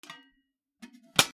やかんのふた 閉める キッチン
『チャ パタン』